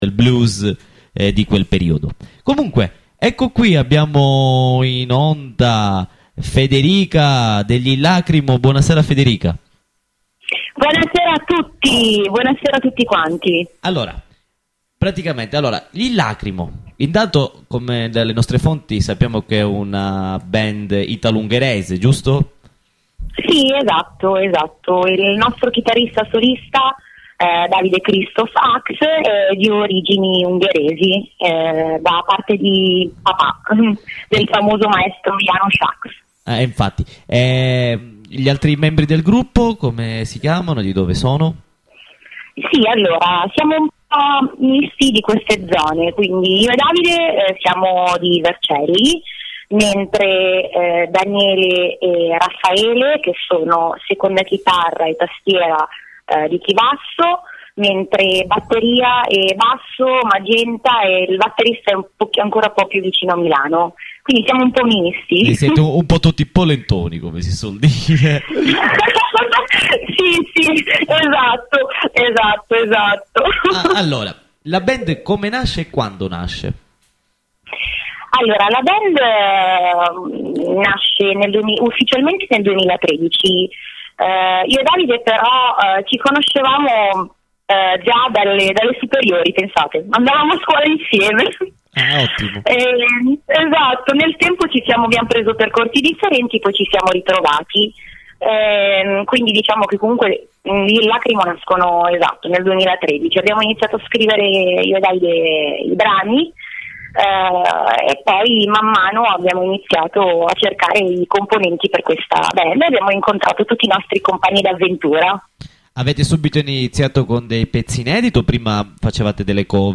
Intervista agli iLLacrimo in occasione dell’uscita del loro primo singolo-video.